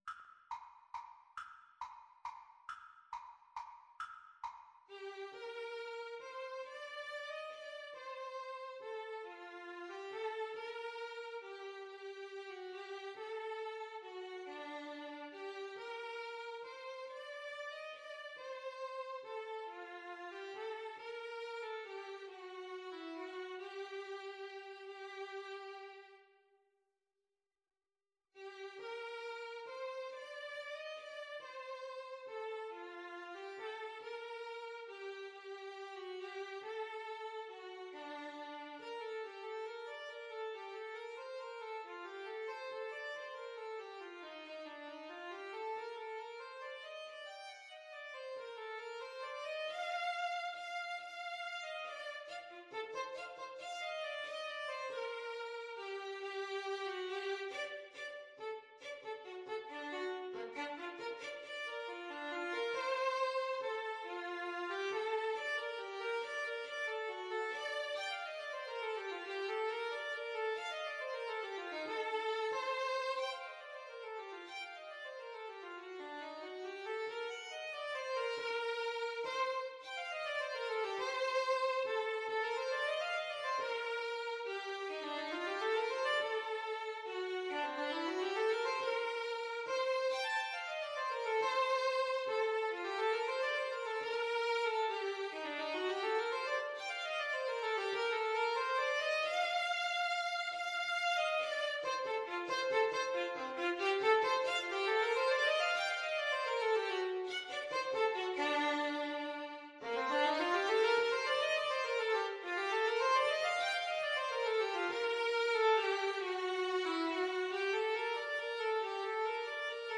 • Unlimited playalong tracks
3/4 (View more 3/4 Music)
Slow one in a bar feel . = c.46
Classical (View more Classical Clarinet-Violin Duet Music)